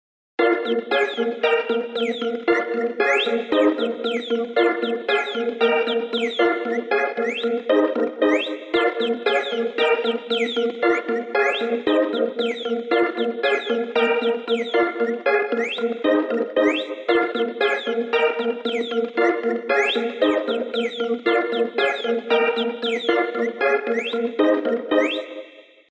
Звуки будильника iPhone